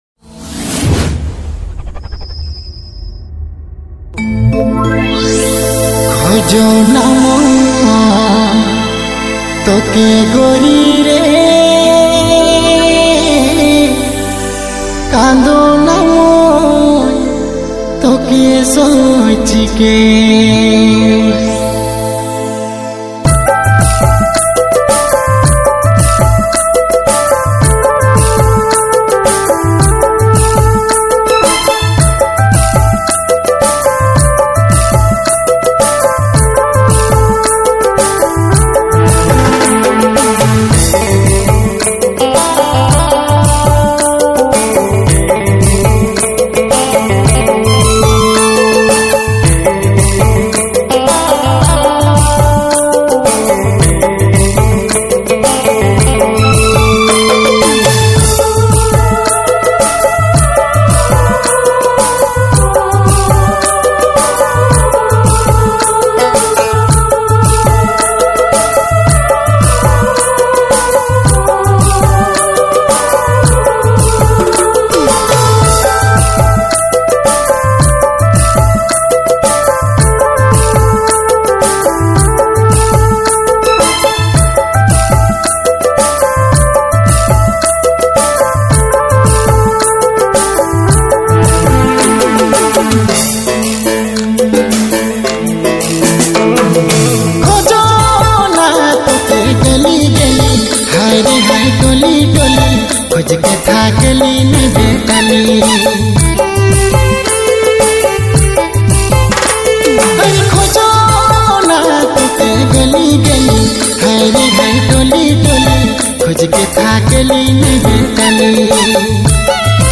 New Nagpuri Dj Songs Mp3 2025